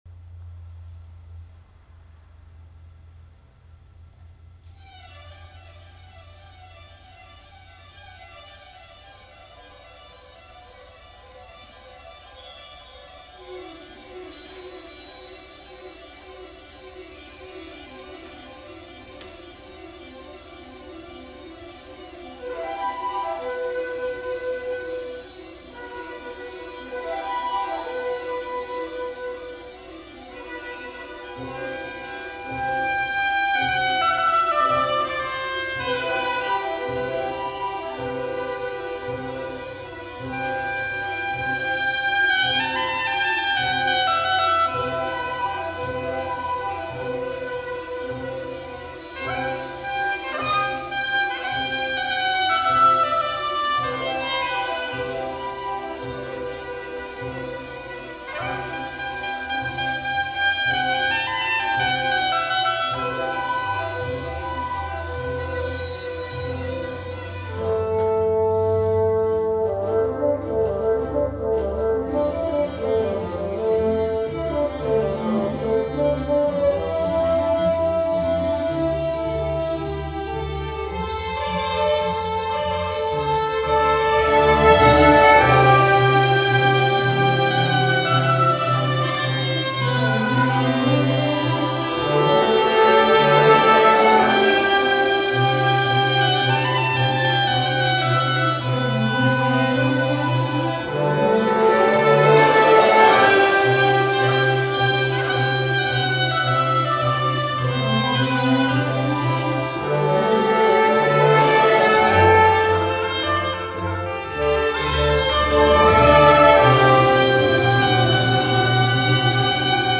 for Oboe and Chamber Orchestra (2003)